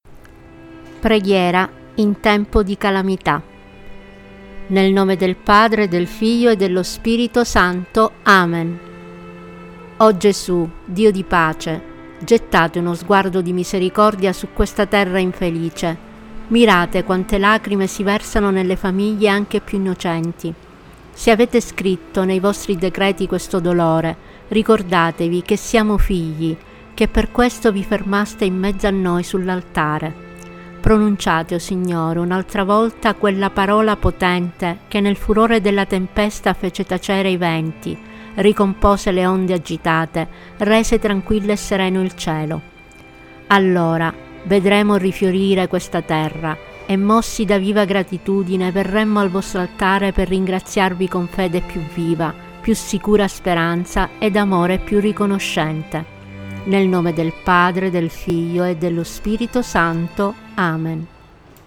Preghiera-in-tempo-di-calamità-audio-e-musica-Tempo-di-preghiera.mp3